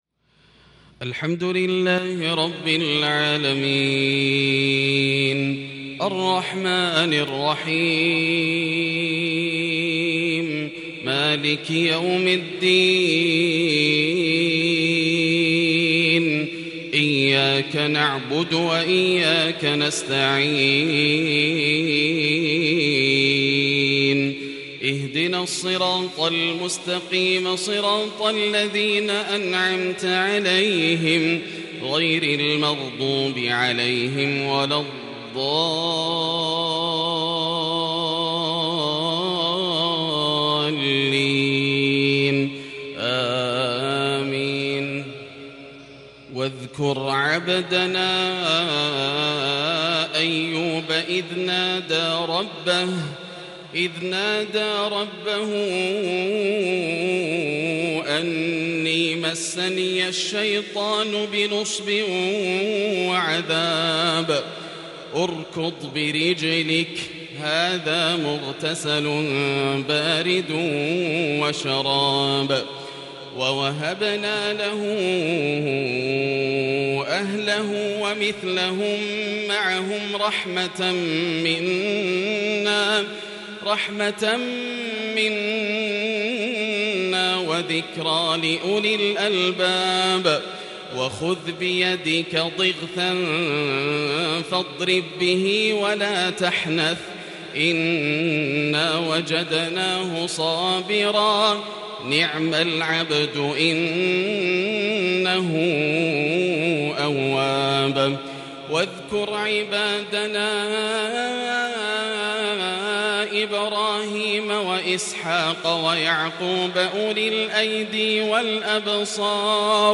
صلاة الفجر لخواتيم سورة ص | الأربعاء 20 صفر 1442هـ Fajr Prayar from Surah Sad | 7/10/2020 > 1442 🕋 > الفروض - تلاوات الحرمين